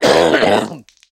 male_cough4.ogg